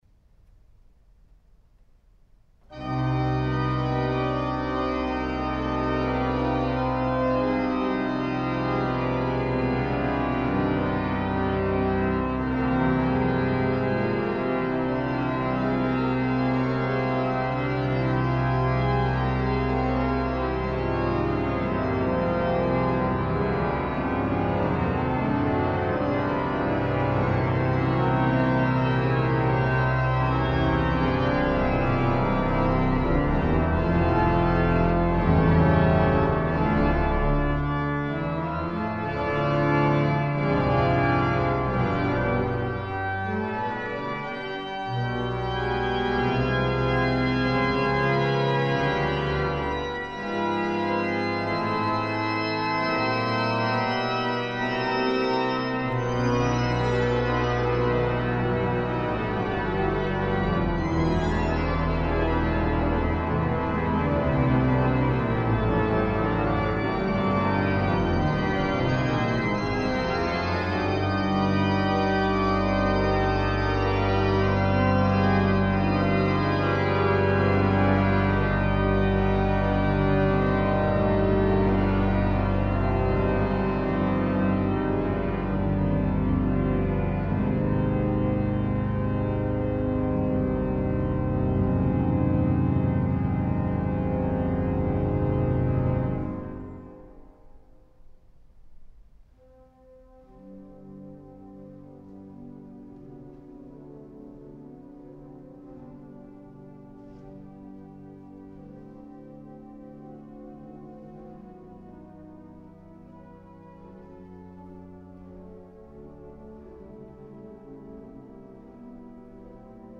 09-Fantasia-In-C-Minor-Op.-21-For-Organ.mp3